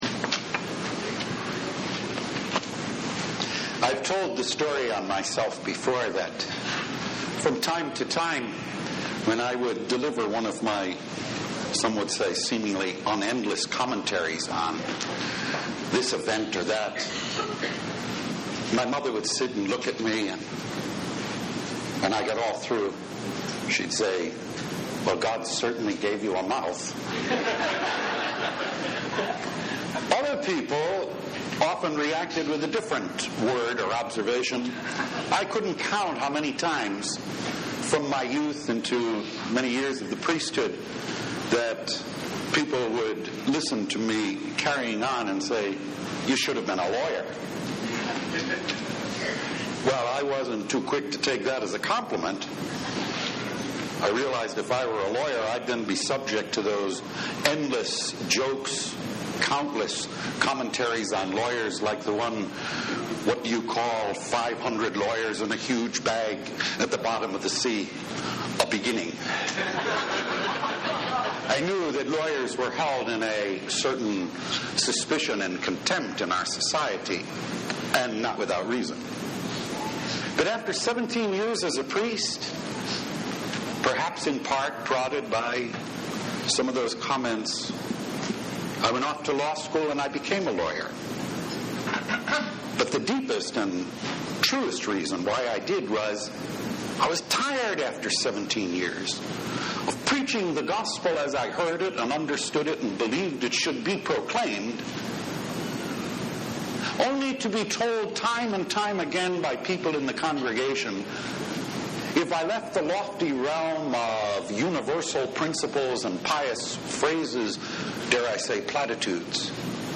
Law – Weekly Homilies